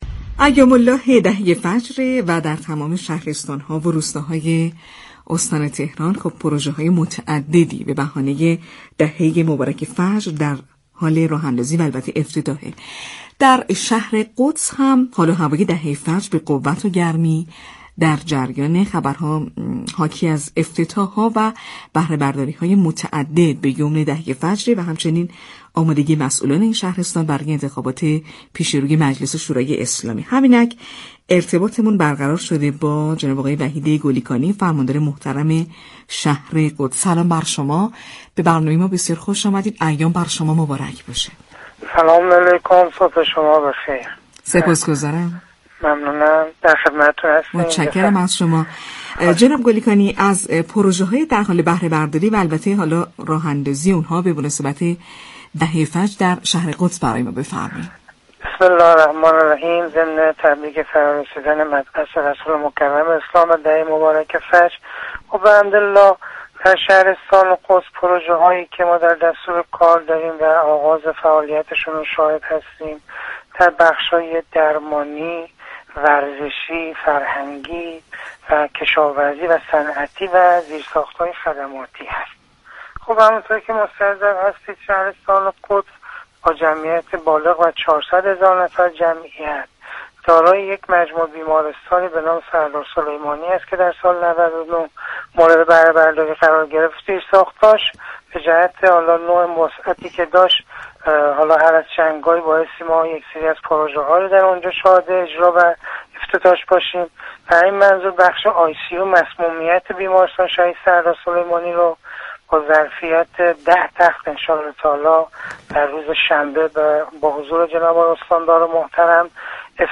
به گزارش پایگاه اطلاع رسانی رادیو تهران، وحید گلی‌كانی فرماندار شهرستان قدس در گفت و گو با «شهر آفتاب» اظهار داشت: همزمان با دهه مبارك فجر پروژه‌های متعددی در حوزه‌های ورزشی، درمانی، فرهنگی، كشاورزی، صنعتی و زیرساخت‌های خدماتی به بهره‌برداری خواهد رسید.